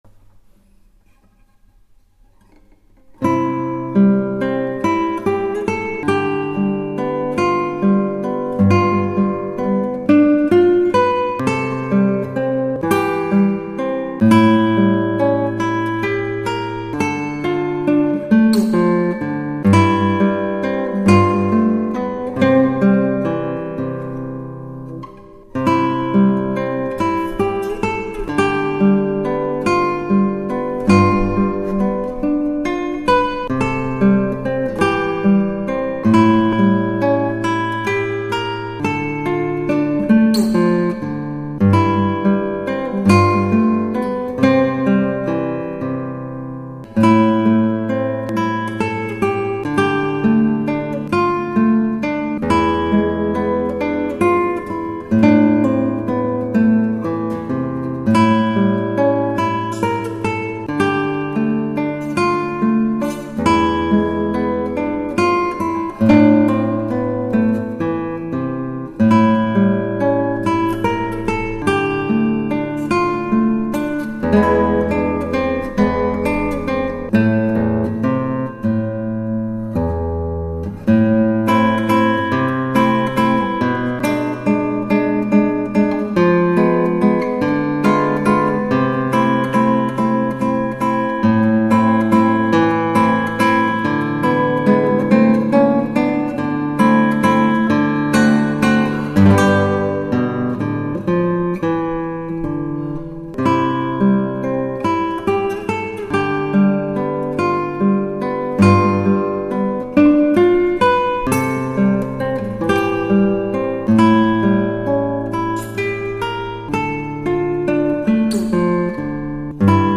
ヘンツェのノクターン、ヤイリギターで